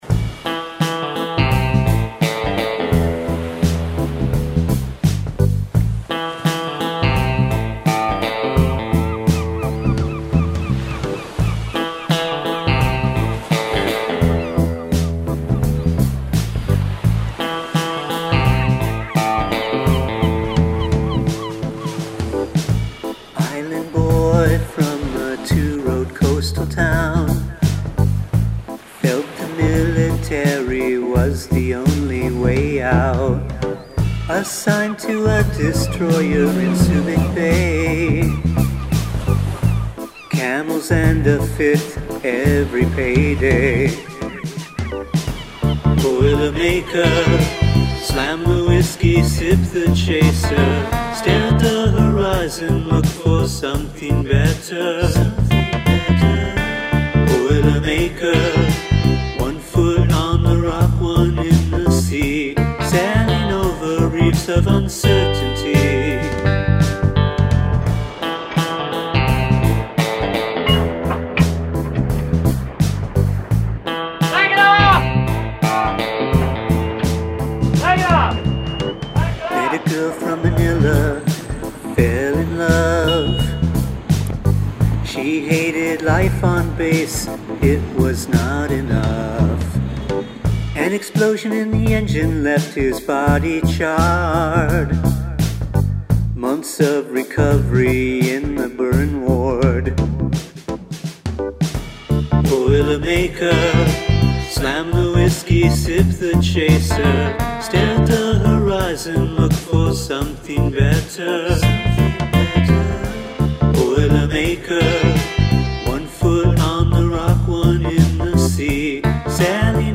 Integrate animal sounds into your song's structure